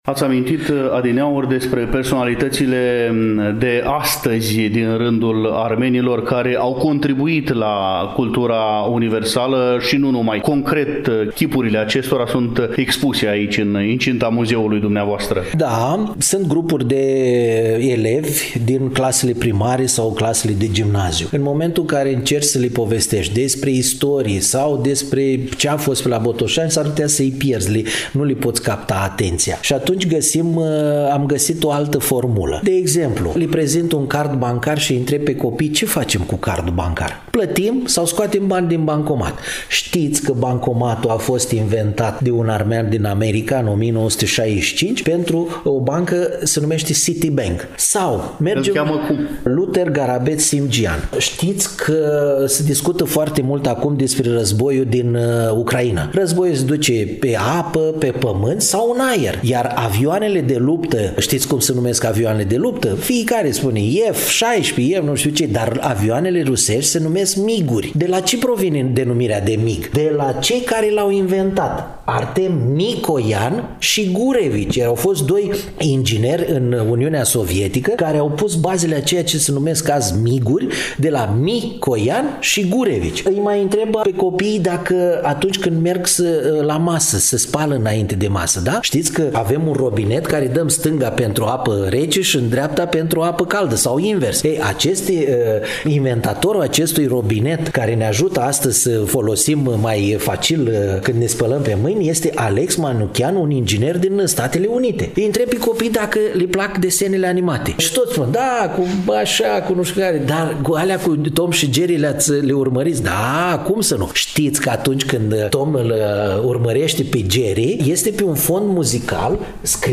În ediția de astăzi a emisiunii Dialog intercultural, relatăm din Comunitatea Armenilor din Municipiul Botoșani, situată pe Strada Armeană, Numărul 20.